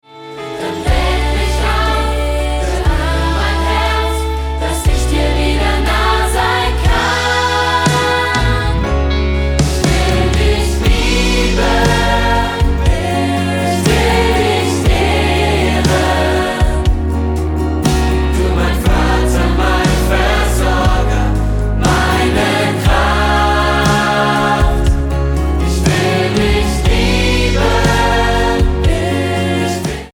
100 Sänger und Live-Band